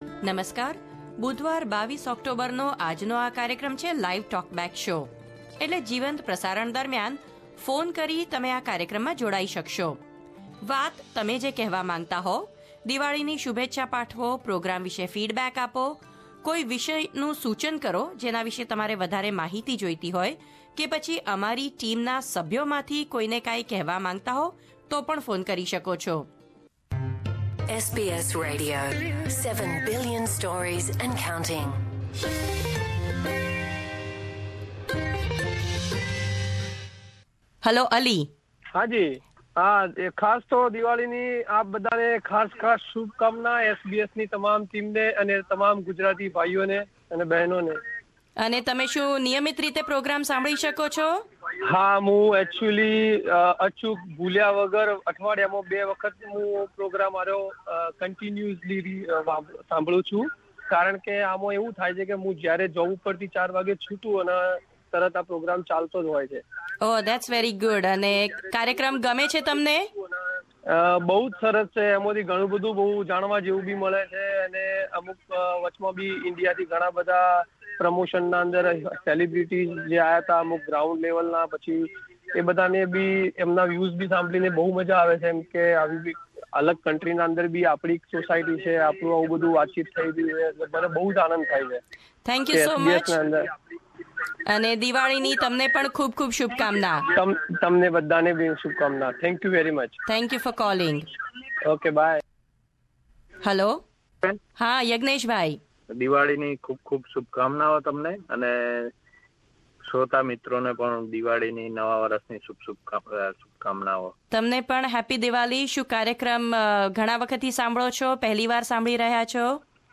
Many of our listeners called in our Diwali special live talk back show.
Here are a few messages from the talkback show.